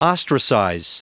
Prononciation du mot ostracize en anglais (fichier audio)